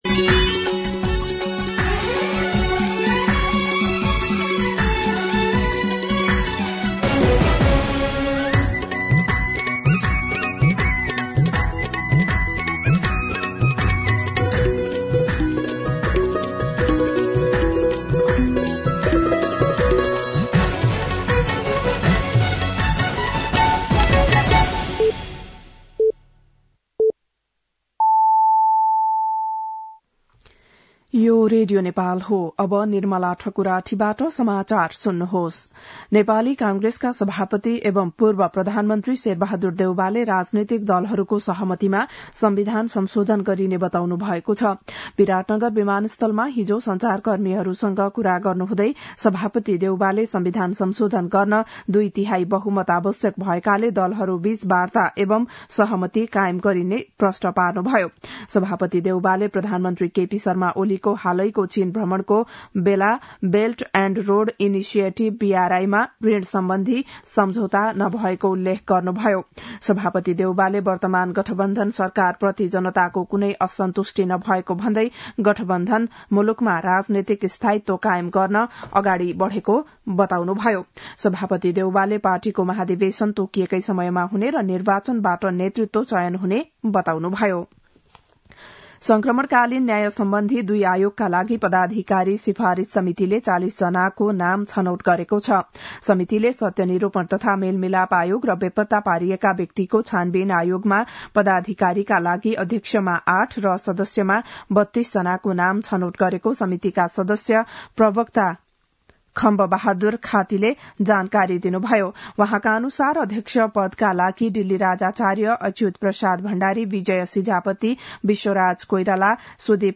बिहान ११ बजेको नेपाली समाचार : २३ मंसिर , २०८१
11am-Nepali-News.mp3